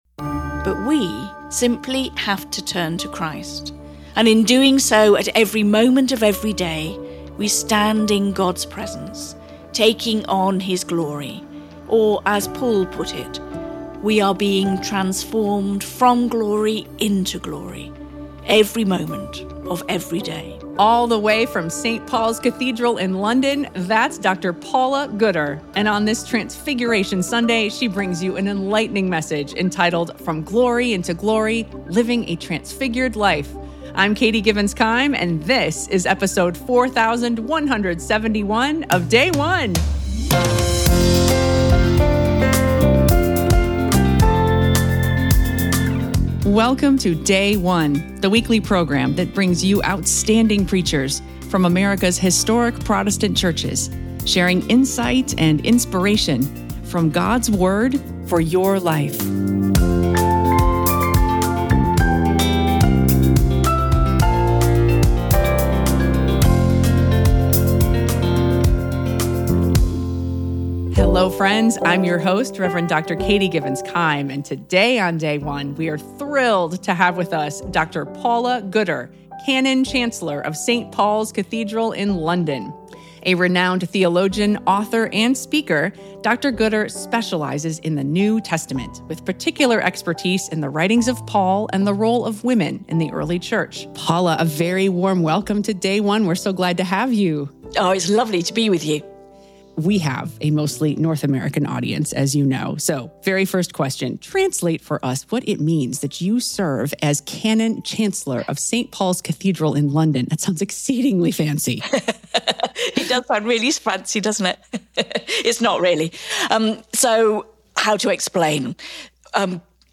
Church of England Transfiguration Sunday, Year C 2 Corinthians 3:12-18